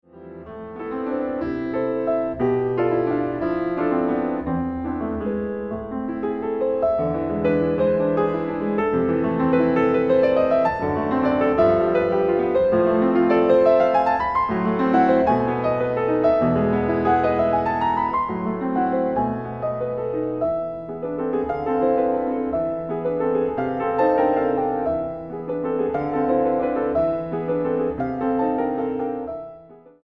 pianista.